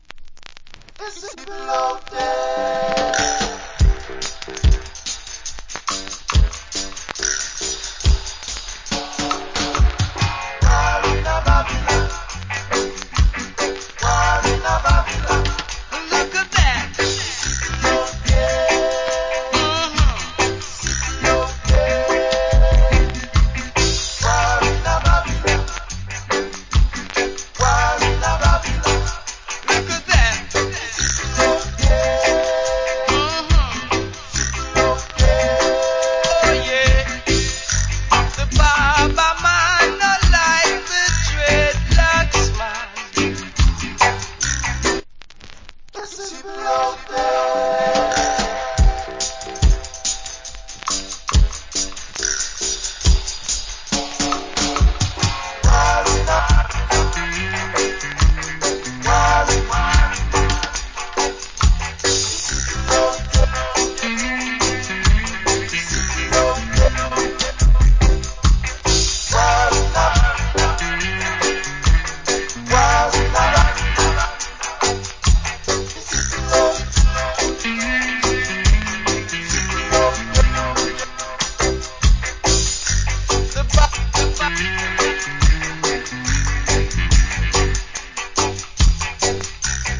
Great Roots Rock Vocal. / Nice Dub.